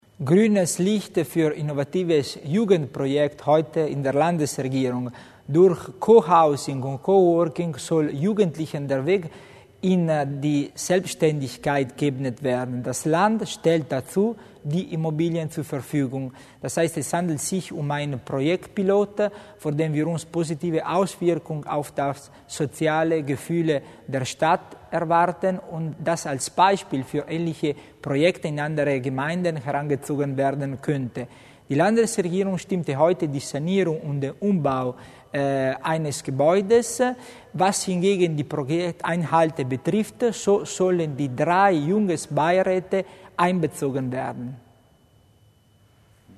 Landesrat Tommasini erläutert das Projekt Cohousing und Coworking